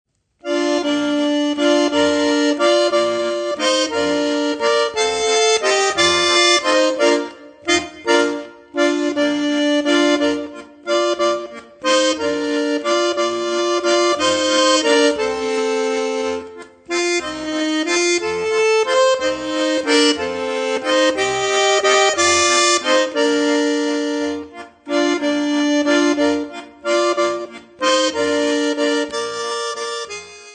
Besetzung: Schwyzerörgeli